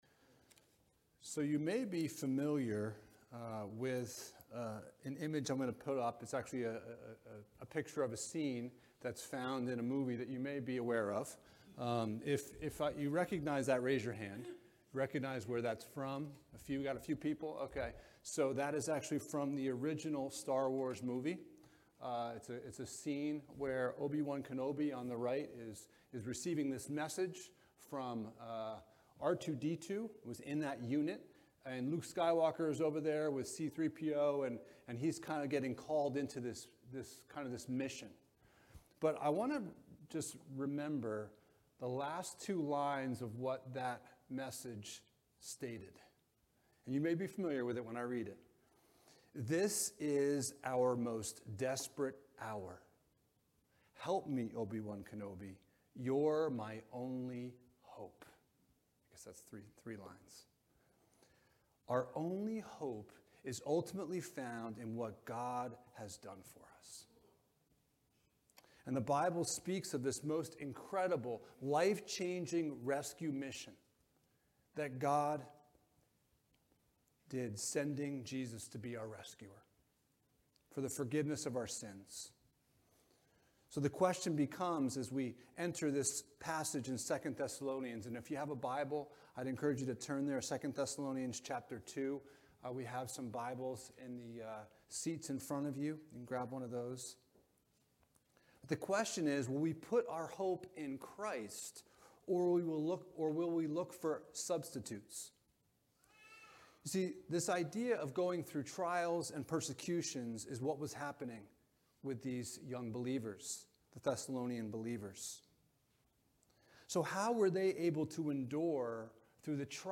Living in Light of Christ's Return Passage: 2 Thessalonians 2:13-17 Service Type: Sunday Morning « Clarity in the Midst of Confusion Does Prayer Really Matter?